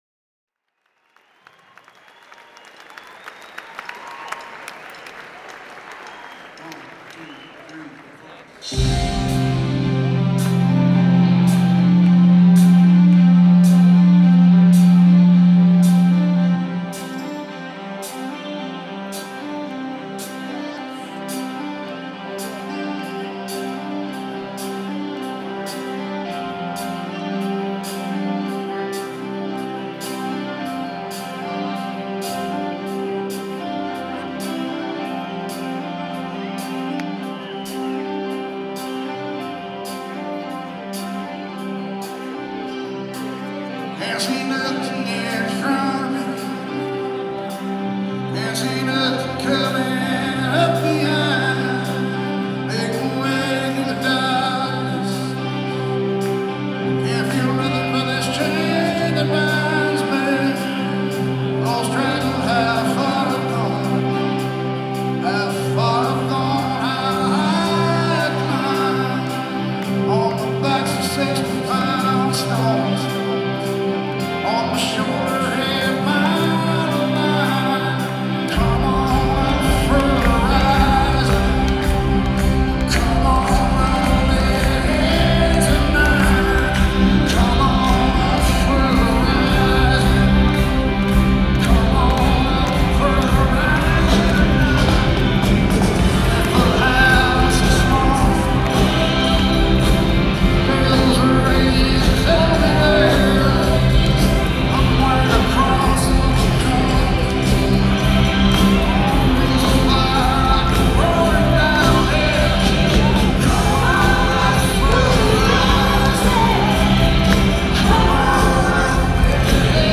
un bel concerto
presso la Convention Hall, Asbury Park, New Jersey